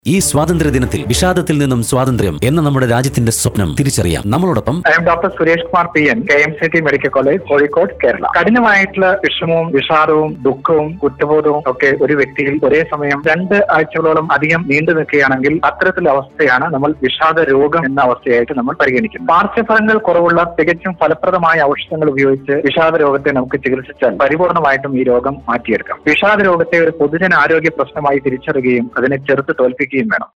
Freedom from depression - brief talk in Red FM on Independence Day 2018